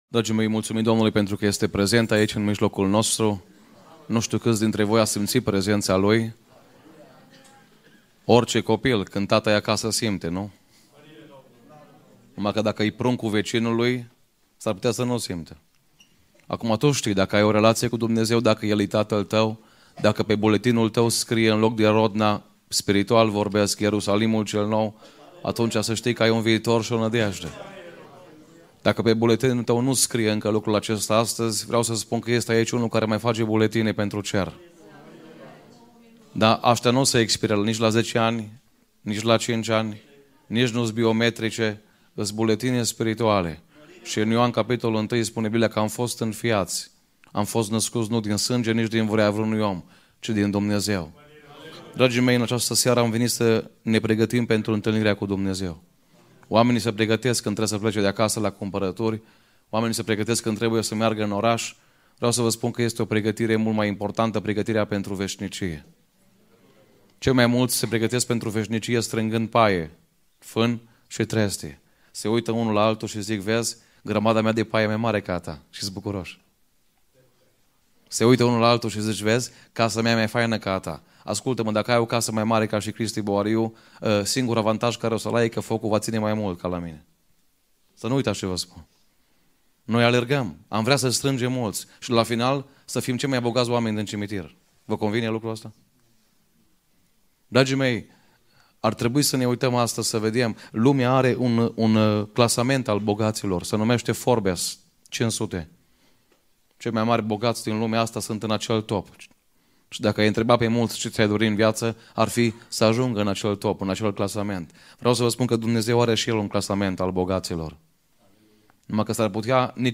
Evanghelizare biserica penticostala Rodna